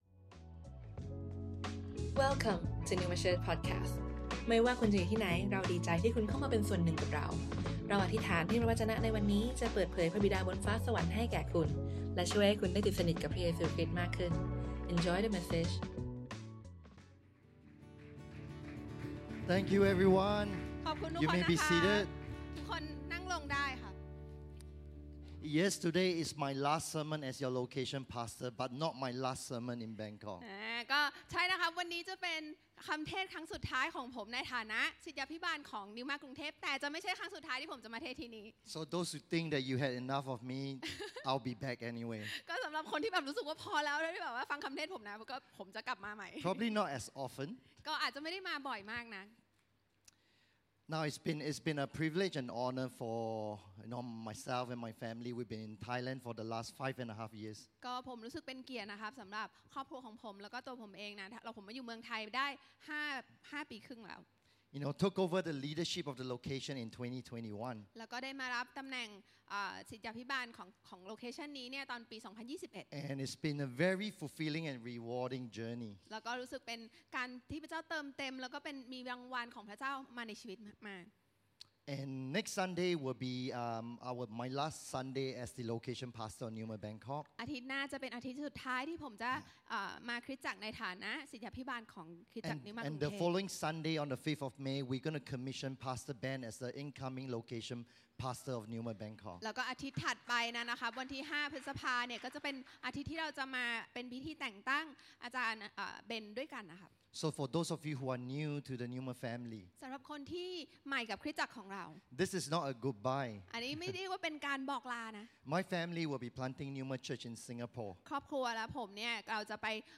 Originally recorded on Sunday 21th April 2024, at Neuma Bangkok.